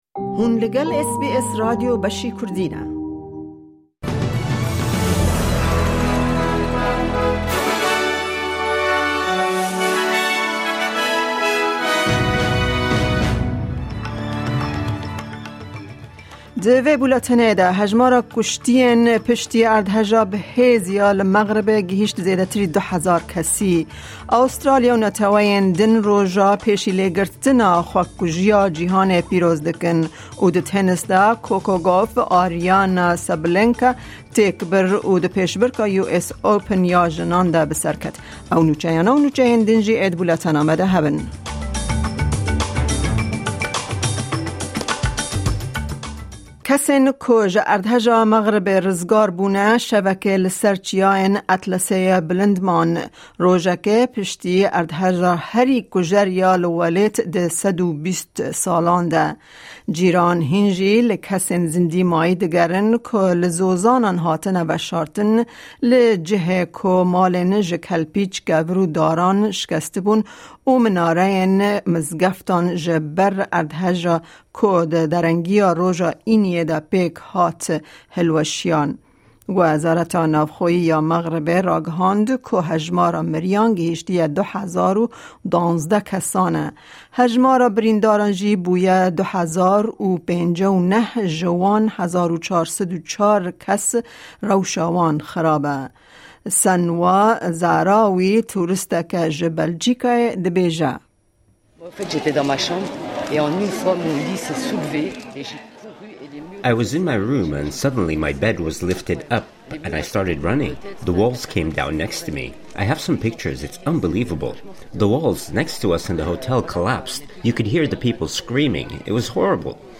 Kurdish News